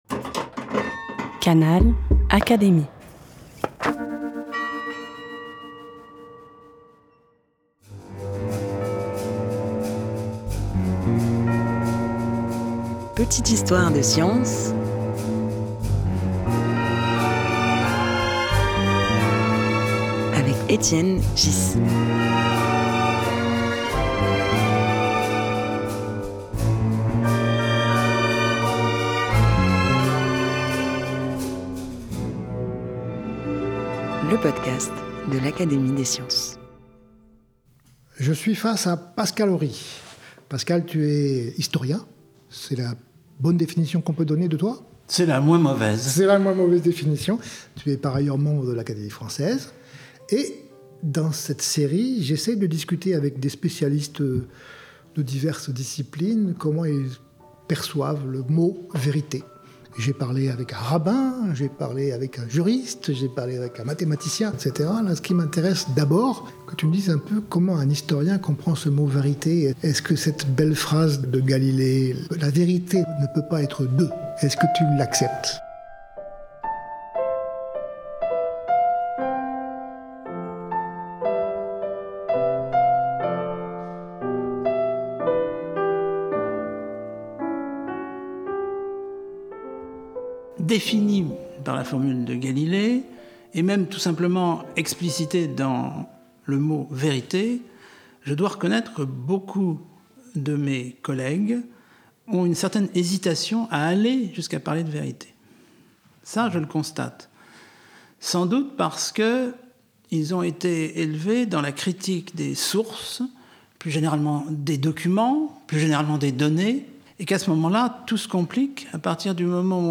Un podcast animé par Étienne Ghys, proposé par l'Académie des sciences.